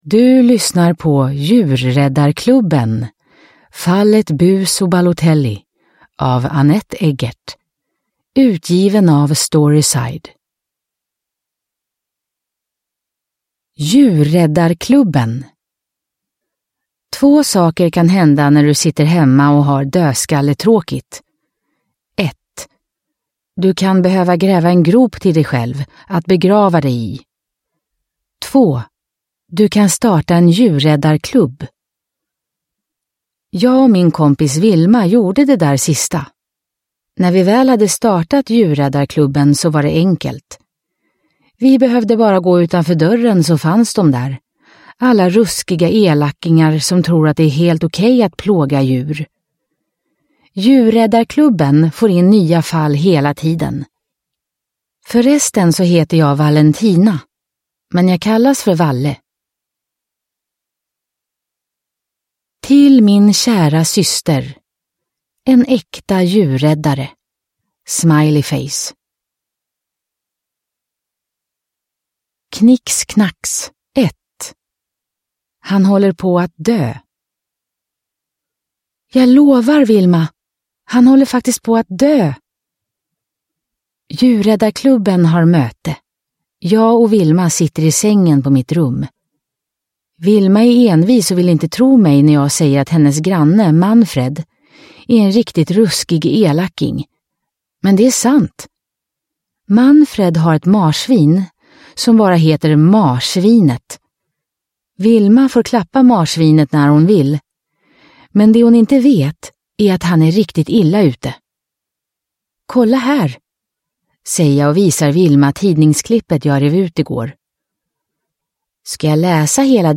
Fallet Bus och Balotelli – Ljudbok – Laddas ner